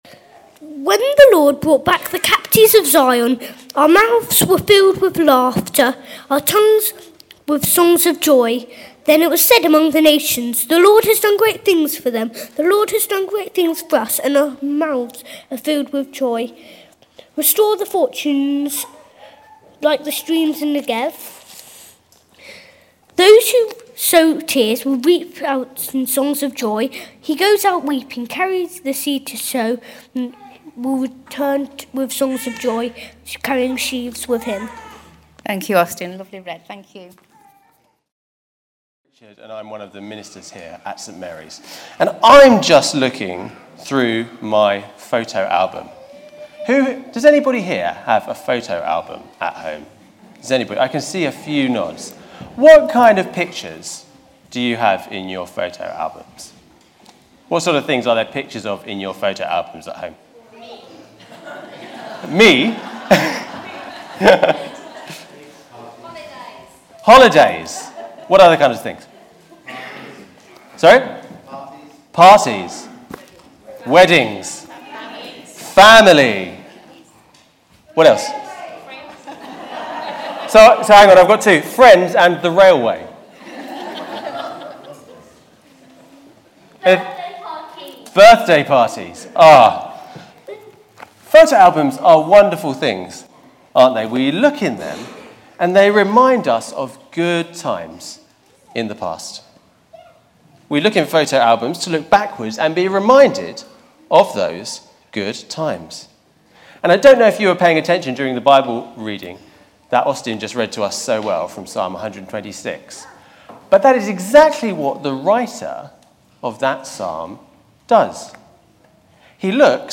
Harvest Festival Sermon
Most sermons at St. Mary's are recorded and are available as computer files (.mp3) so that you can listen to them on your computer at home, or download them to transfer them to your portable music player (eg iPod).